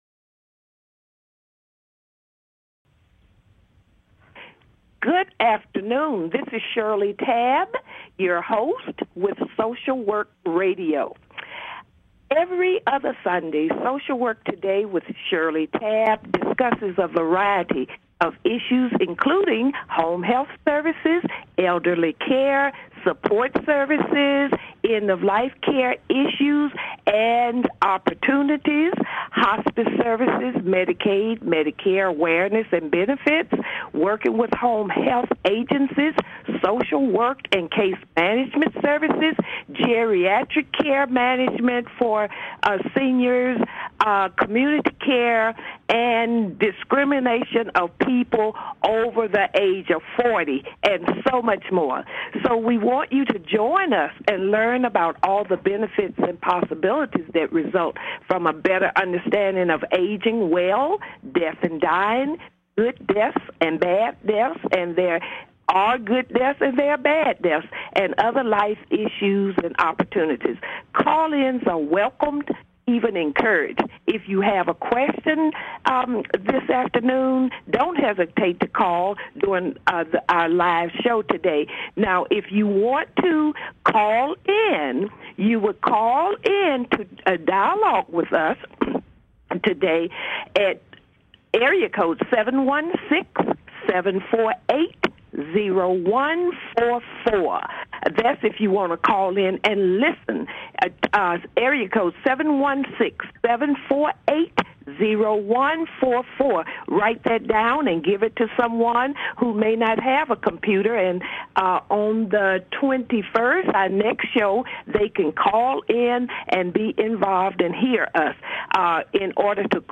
Talk Show Episode, Audio Podcast, Social Work Today and Over-Qualified and Over 50! on , show guests , about unemployment,age discrimination,baby boomers,Finding a Job,seeking employment, categorized as Business,Politics & Government,Self Help,Variety